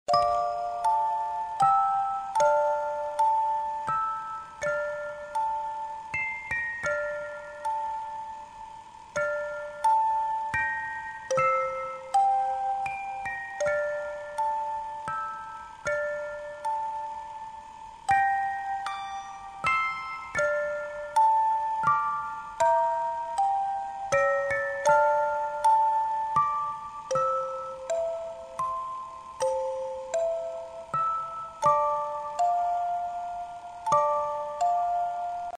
• Качество: 320, Stereo
спокойные
без слов
Melodic
Музыкальная шкатулка
саундтрек